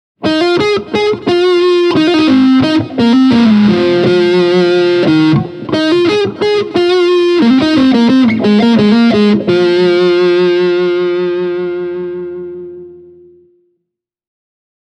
Tässä muutama esimerkkisoundi:
Blackstar ID:60TVP – Hamer Crunch flanger reverb